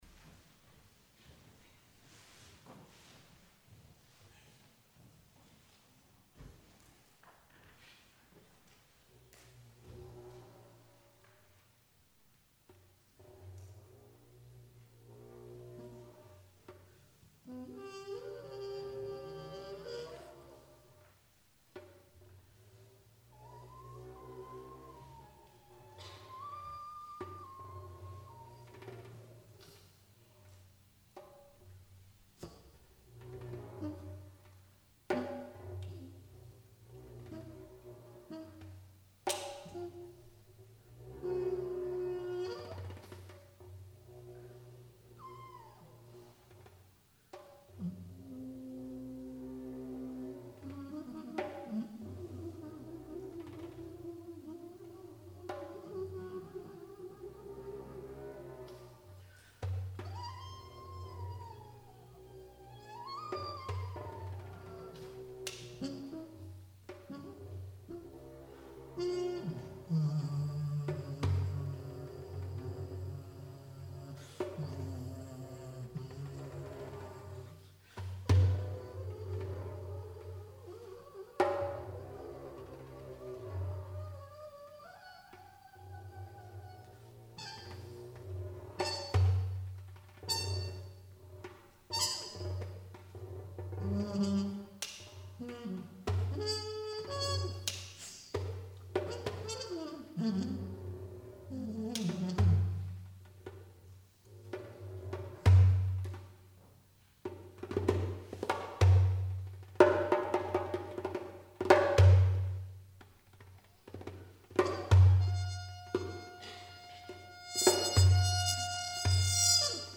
Trumpet solo and ad lib Percussion
at Keyaki Hall, Tokyo, Japan